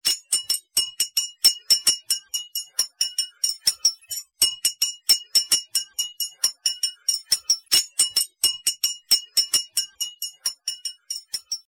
Tiếng chuông Lấy Rác
Tiếng Cầm điện thoại Tiếng gõ kẻng Lấy Rác, Đổ Rác Leng keng leng keng… (Mẫu số 2)
Thể loại: Tiếng chuông, còi
Description: Tiếng chuông thu gom rác, âm thanh leng keng đặc trưng của xe rác, chuông báo giờ đổ rác, tiếng chuông nhắc nhở vệ sinh môi trường, sound effect xe thu gom rác phát thanh, âm thanh thông báo đổ rác đúng giờ, chuông báo thu gom chất thải, nhạc cảnh báo giờ đổ rác sinh hoạt, âm thanh môi trường đô thị...
tieng-chuong-lay-rac-www_tiengdong_com.mp3